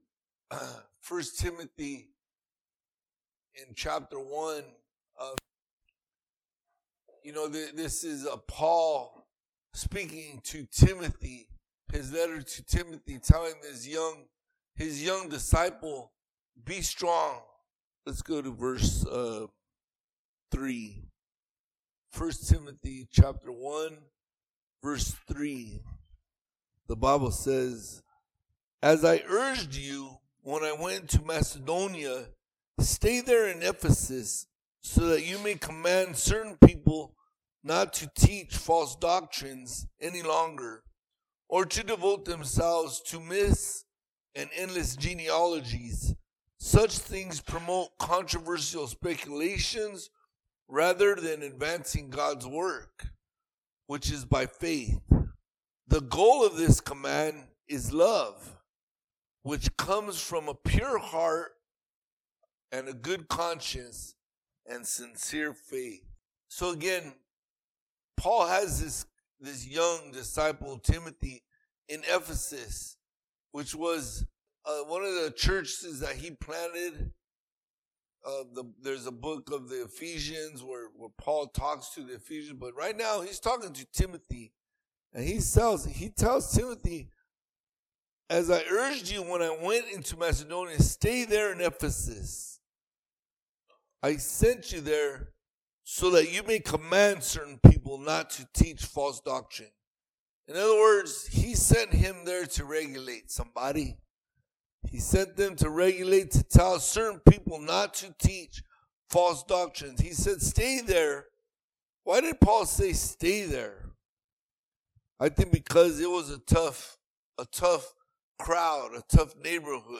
Podcast (piru-community-church-sermons): Play in new window | Download